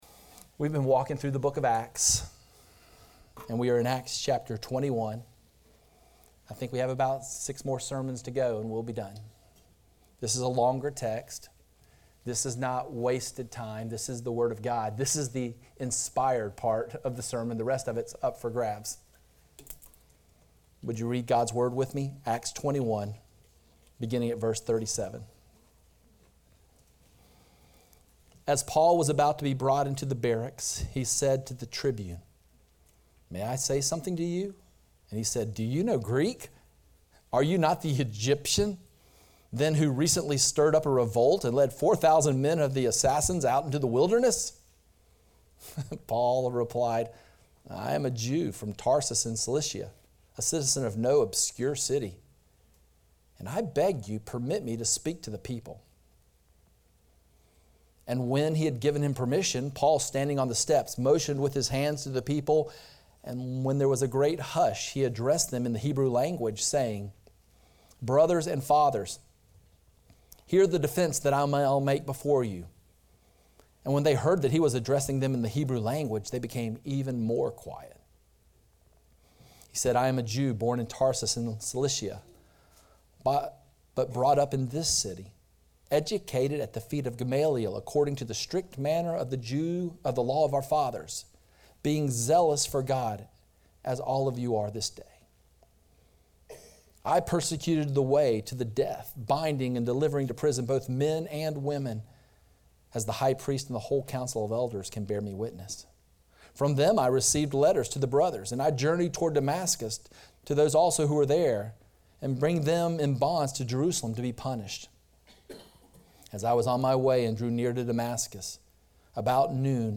Horizon Church Sermon Audio